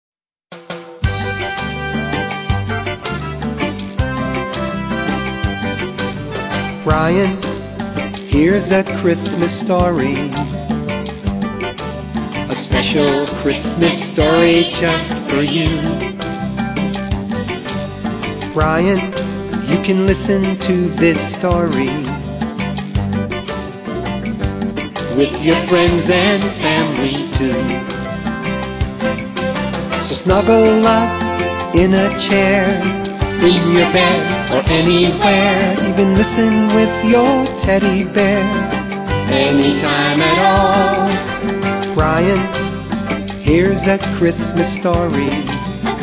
Format: Audio Story CD